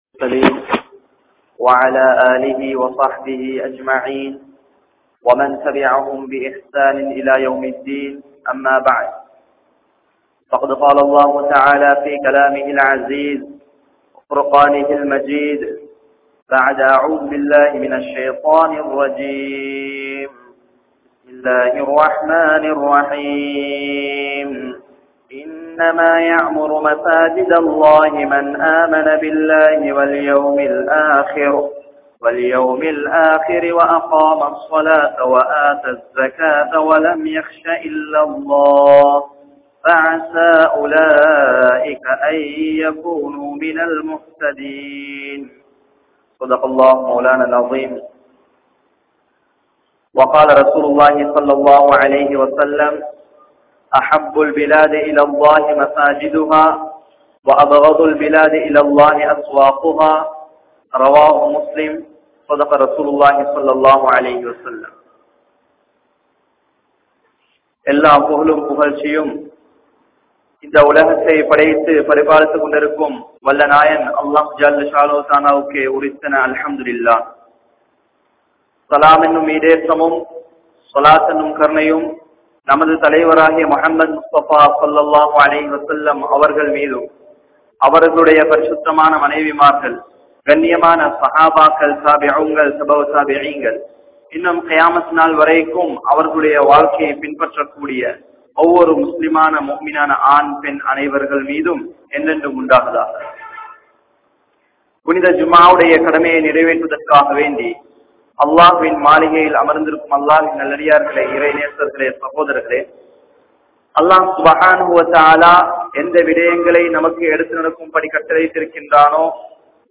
Masjidhin Niruvaha Safaikku Yaar Thahuthi? (மஸ்ஜிதின் நிருவாக சபைக்கு யார் தகுதி?) | Audio Bayans | All Ceylon Muslim Youth Community | Addalaichenai
Karawira Jumua Masjidh